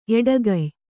♪ eḍagay